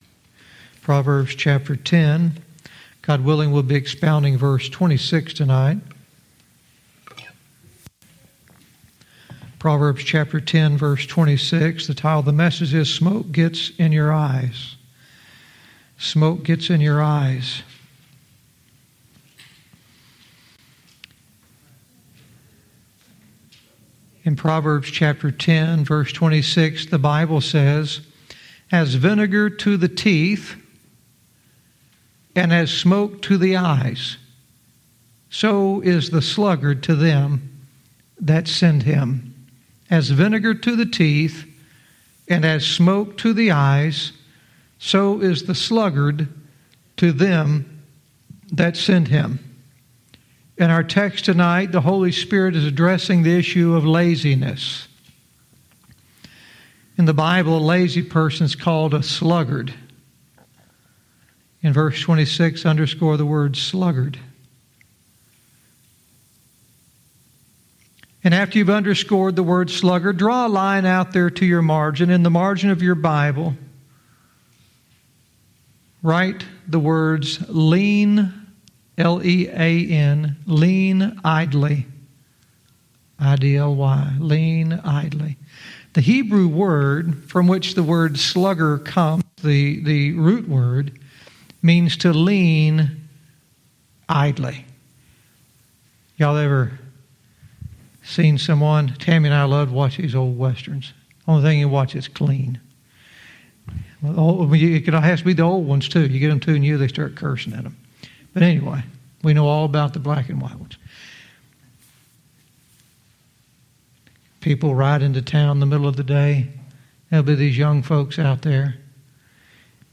Verse by verse teaching - Proverbs 10:26 "Smoke Gets In Your Eyes"